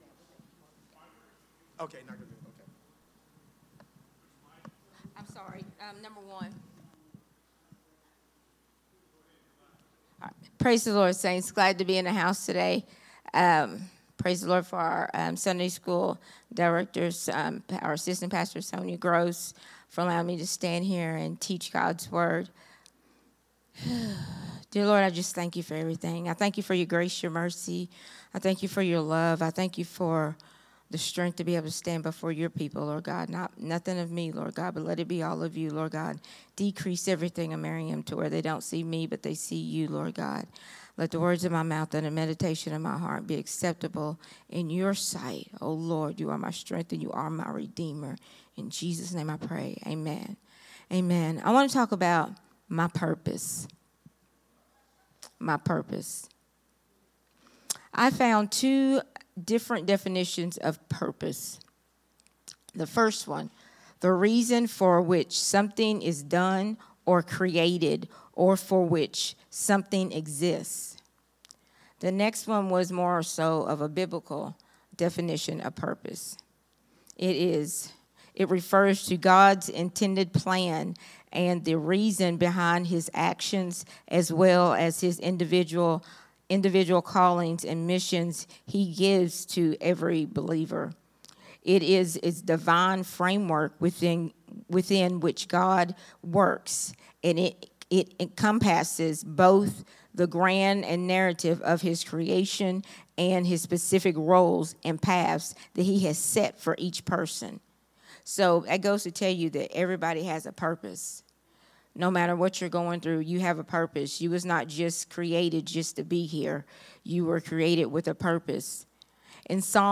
a Sunday Morning Risen Life teaching